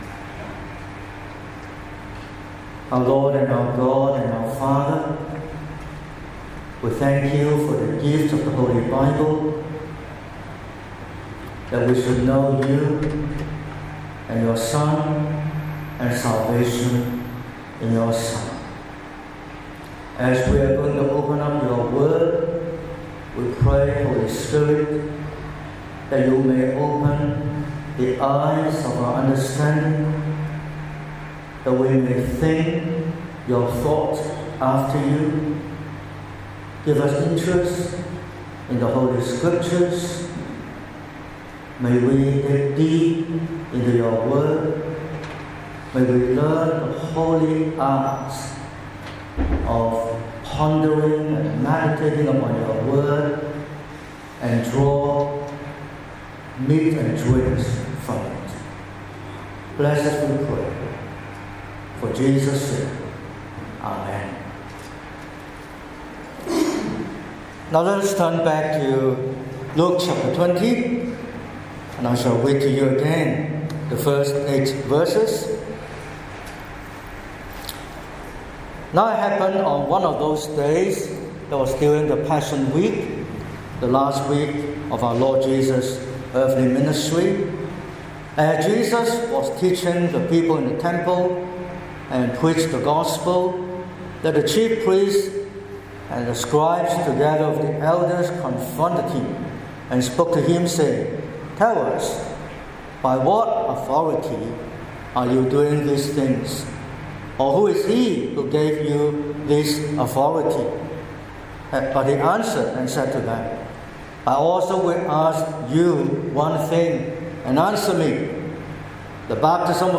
08/03/2026 – Morning Service: By what authority?